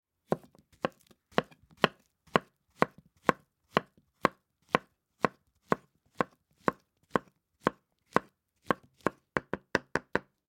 Звук нарезания банана ножом на доске